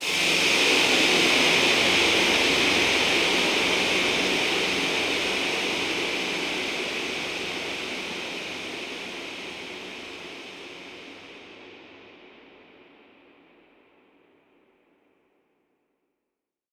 Index of /musicradar/shimmer-and-sparkle-samples/Filtered Noise Hits
SaS_NoiseFilterC-05.wav